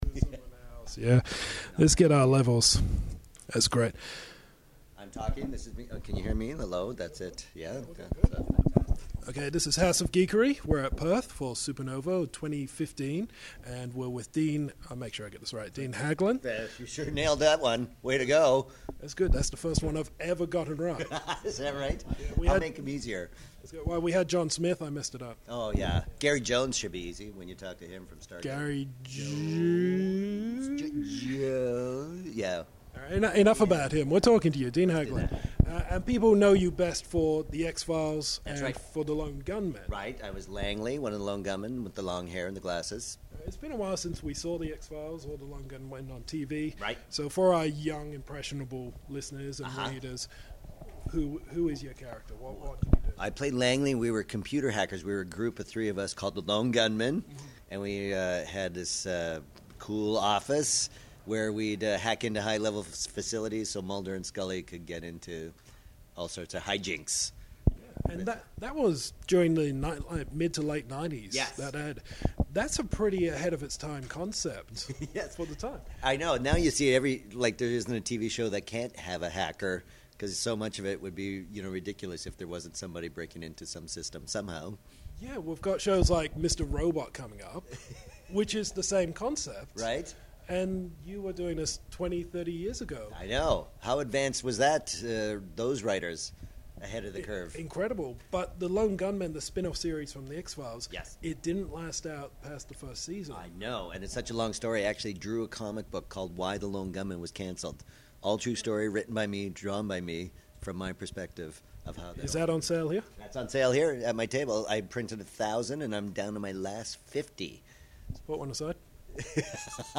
Exclusive Interview: Dean Haglund Talks ‘Lone Gunmen’, Watch Lists and 9/11
During this past Supanova Convention we had the chance to sit down with X-Files and Lone Gunman star Dean Haglund, who played the conspiracy driven hacker Langly. We talked about hacking in the media, conspiracy nuts and being watched by the government.